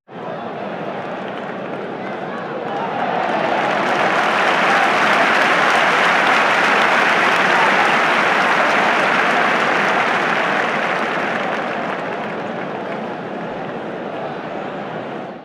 Aplausos y bravos del público en una plaza de toros